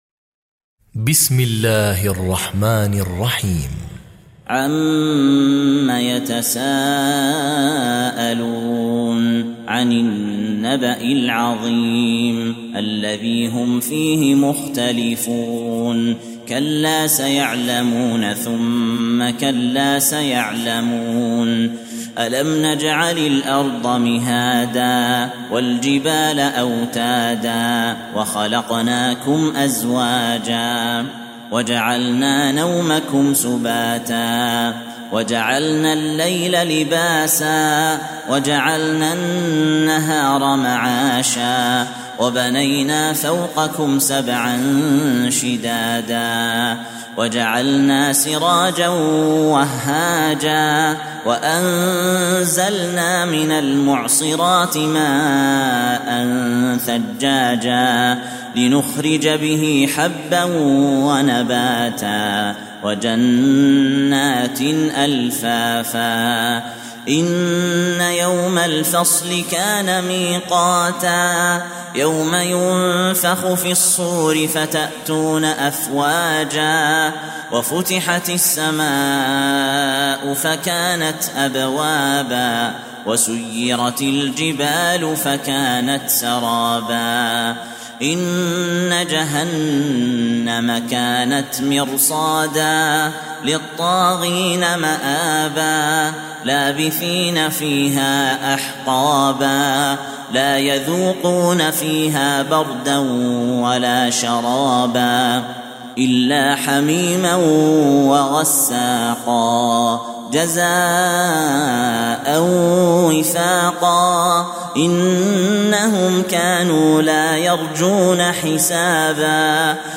78. Surah An-Naba' سورة النبأ Audio Quran Tarteel Recitation
حفص عن عاصم Hafs for Assem